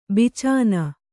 ♪ bicāna